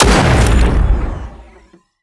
AntiMaterialRifle_3p_02.wav